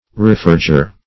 Reforger \Re*for"ger\ (r?*f?r"j?r), n. One who reforges.